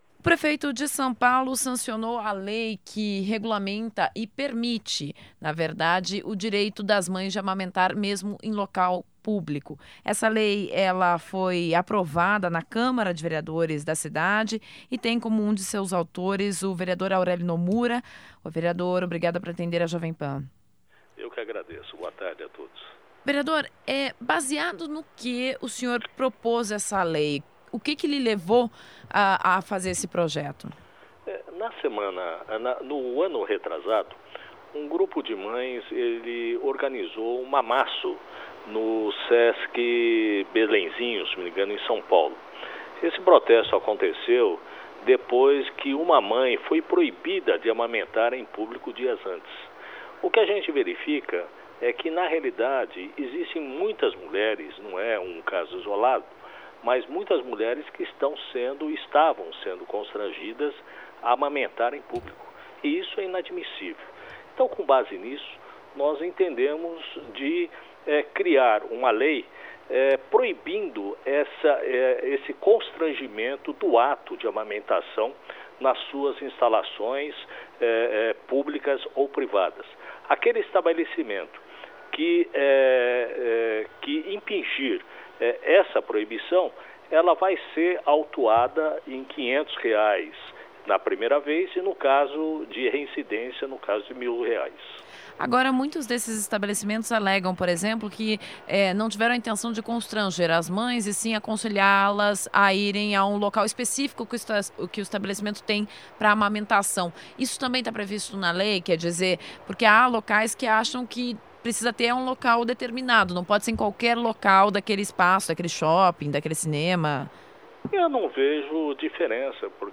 Rádio Jovem Pan entrevista vereador Aurélio Nomura